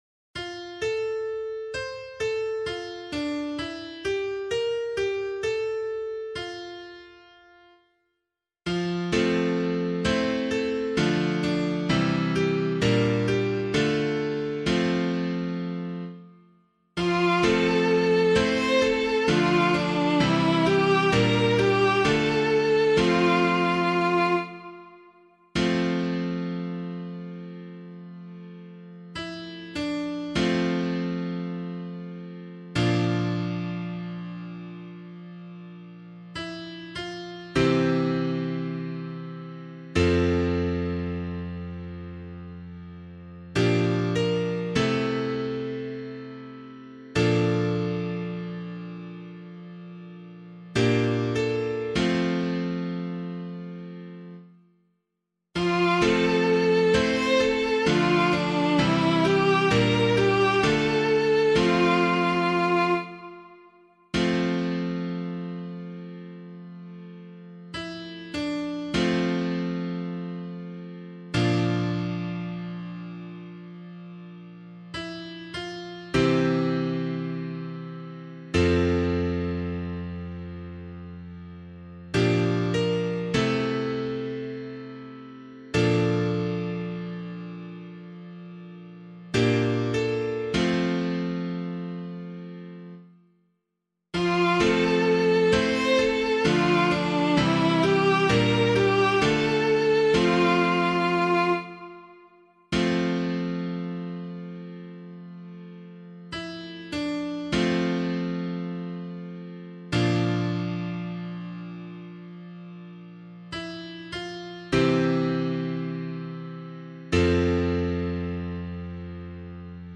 039 Ordinary Time 5 Psalm A [APC - LiturgyShare + Meinrad 6] - piano.mp3